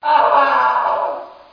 scream1.mp3